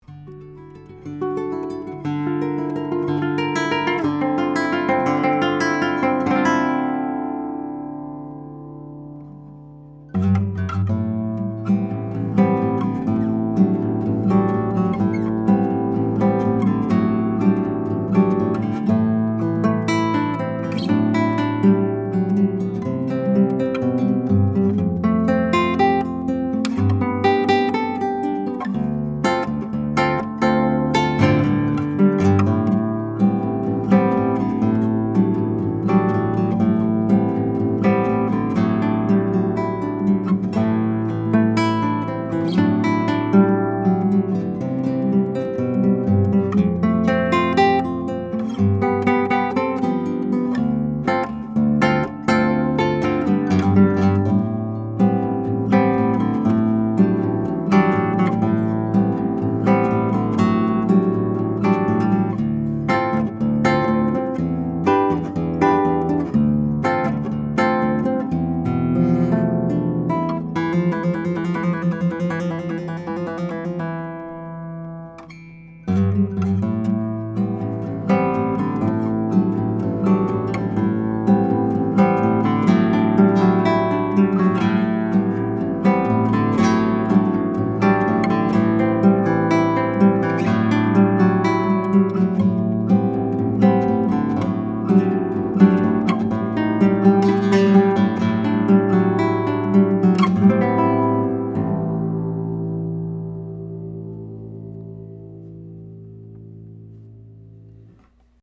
10-String Guitar